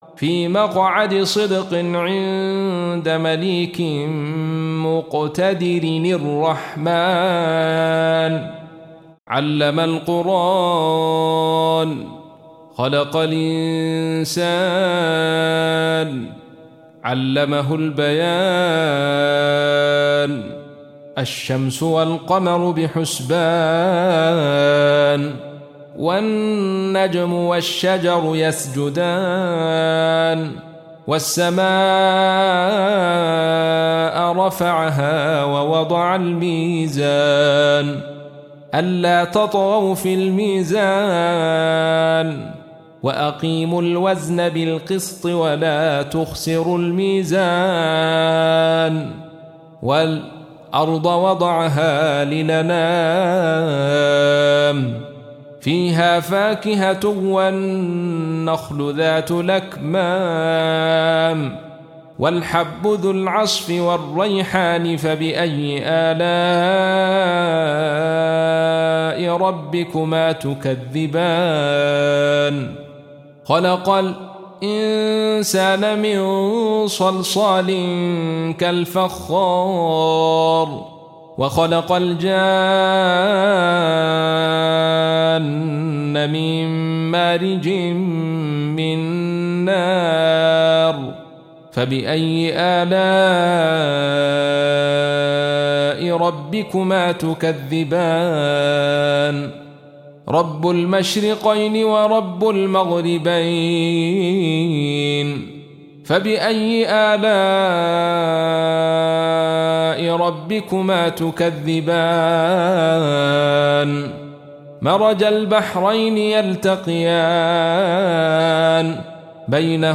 55. Surah Ar-Rahm�n سورة الرحمن Audio Quran Tarteel Recitation
حفص عن عاصم Hafs for Assem
Surah Repeating تكرار السورة Download Surah حمّل السورة Reciting Murattalah Audio for 55.